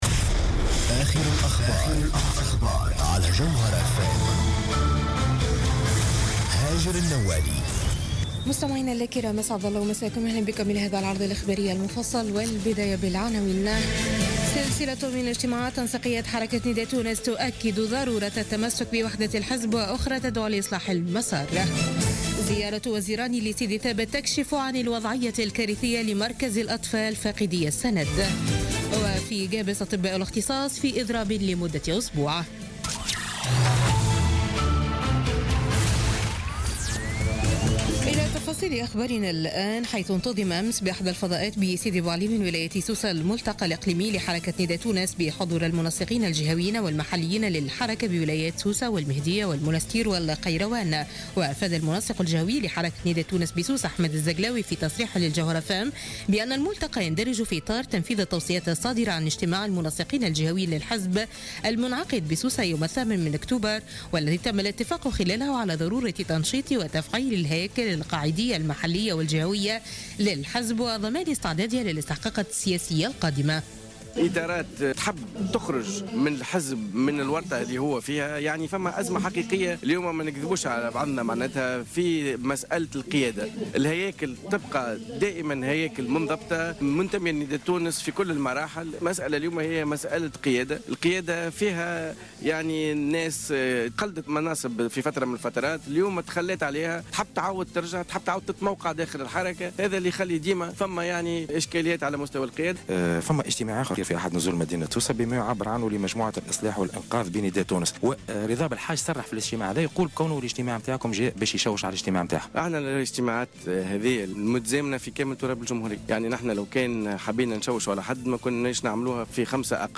نشرة أخبار منتصف الليل ليوم الإثنين 31 أكتوبر 2016